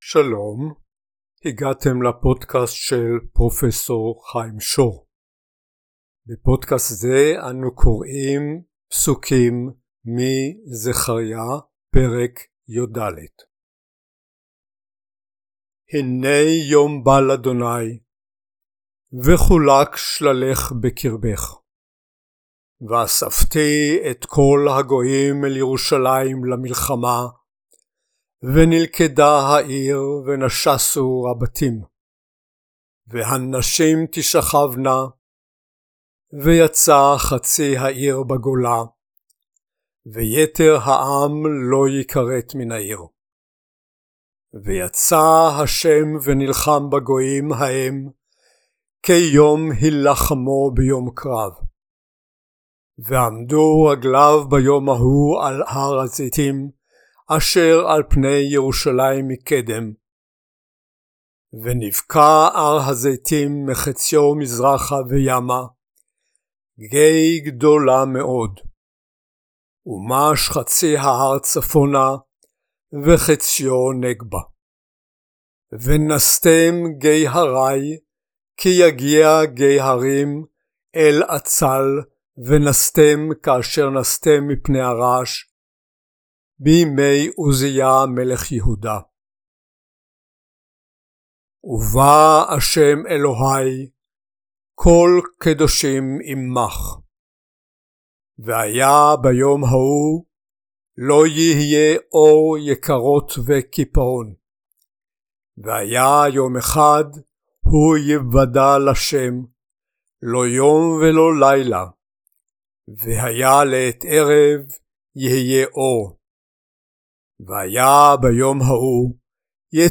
In this post, we read end-time scenarios, as prophesized by Zechariah (verses from Chapter 14).